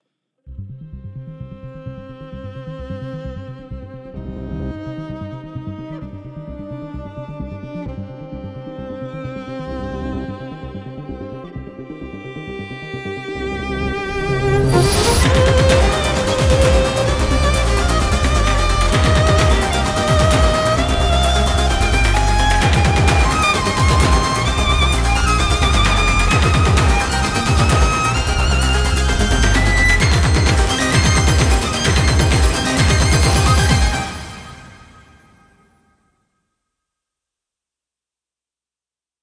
———————————————— Production Music Examples ————————————————